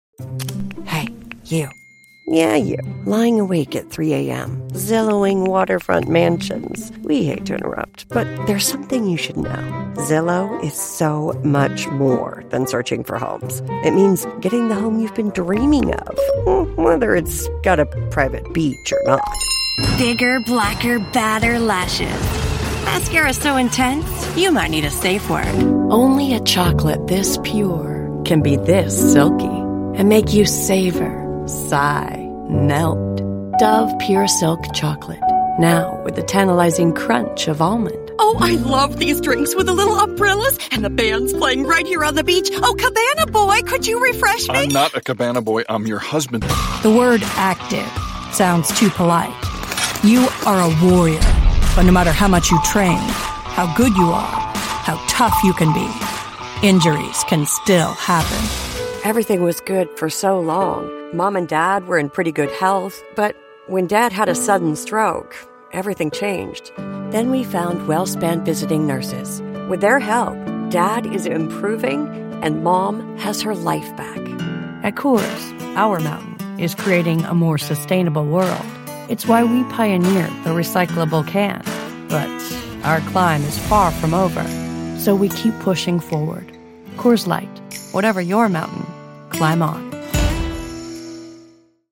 Engels (Amerikaans)
Diep, Natuurlijk, Vriendelijk, Warm, Stoer
Commercieel